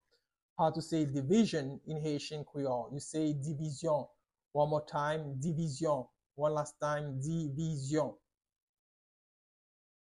Pronunciation:
8.How-to-say-Division-in-Haitian-Creole-–-Divizyon-with-pronunciation.mp3